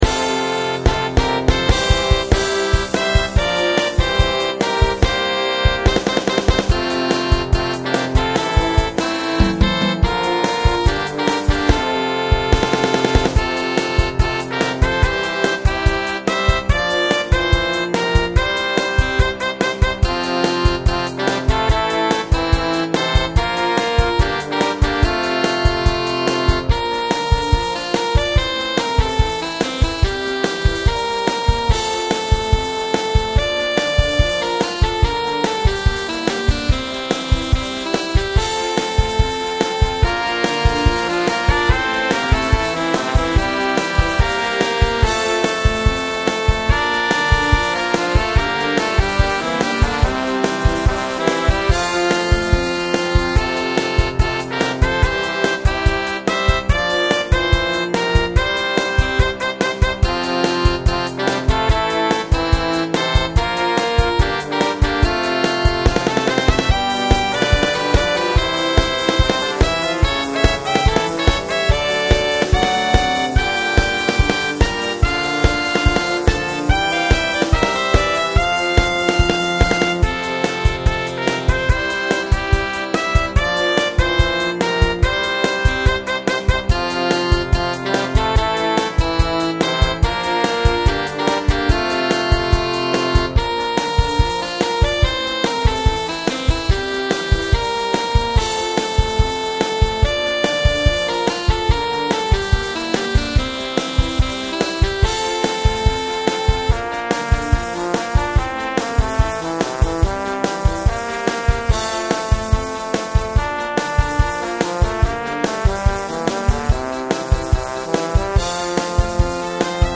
ステレオ   悩みどころでしたが全体のバランスからSGMに決定しました。